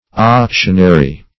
Search Result for " auctionary" : The Collaborative International Dictionary of English v.0.48: Auctionary \Auc"tion*a*ry\, a. [L. auctionarius.] Of or pertaining to an auction or an auctioneer.